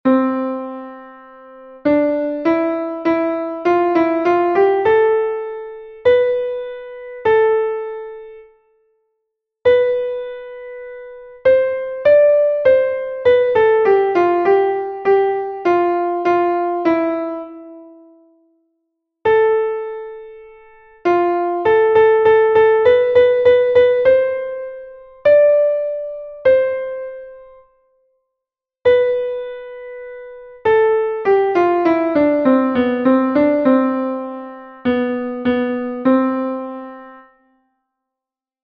1. Tune and metronome: